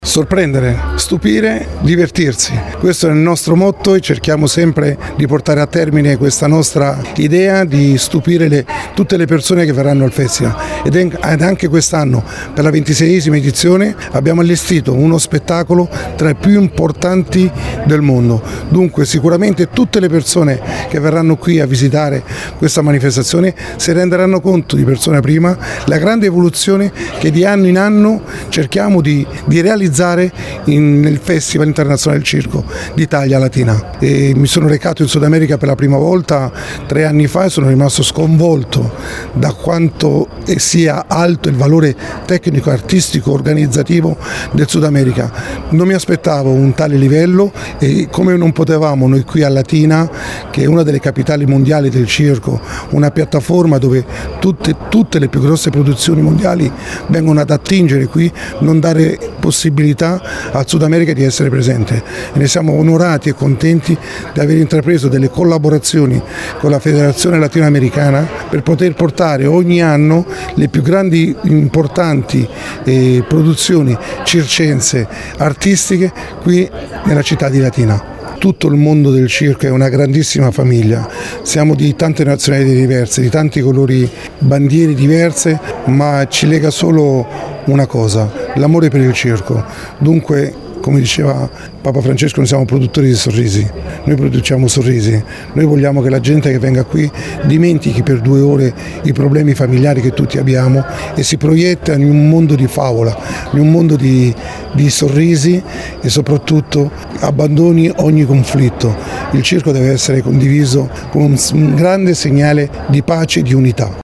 Latina ha ufficialmente acceso i riflettori sulla 26ª edizione del Festival Internazionale del Circo d’Italia, con la conferenza stampa di presentazione che si è svolta nella suggestiva cornice della Cittadella del Circo.